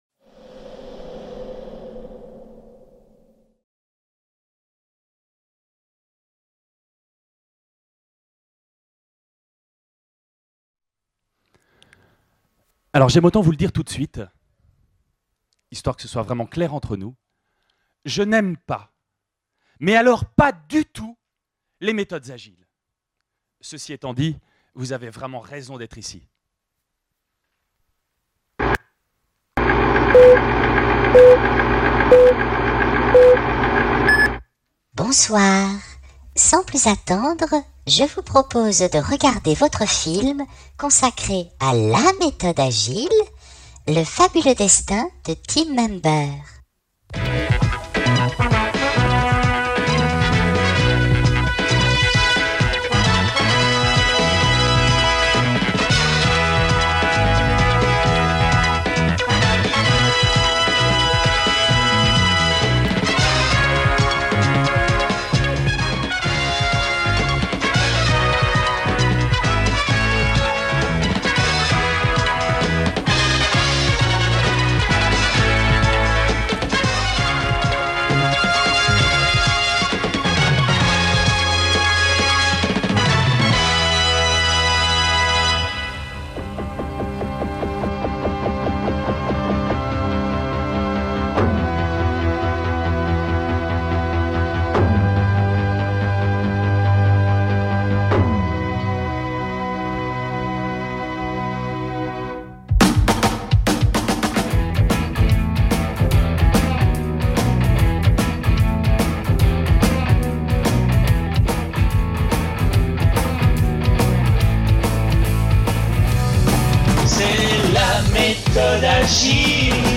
La conférence : Pour être un bon informaticien il faut être un peu philosophe.